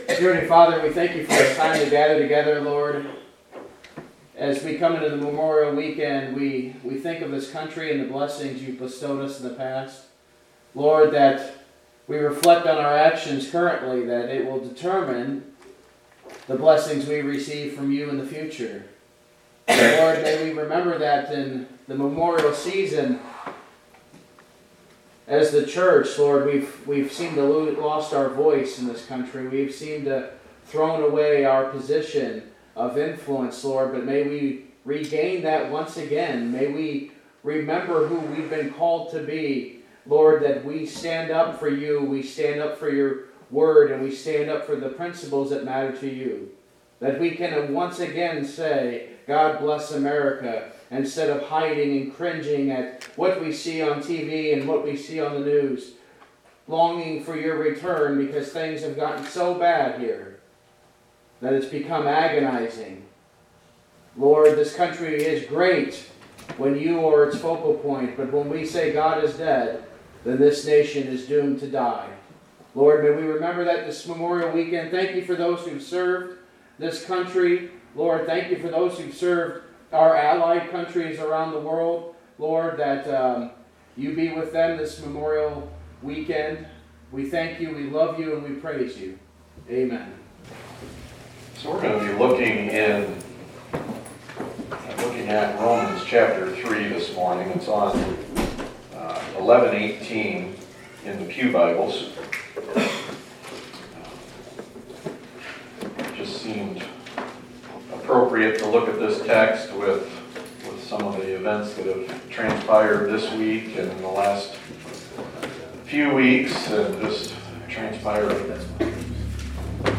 Topical Sermons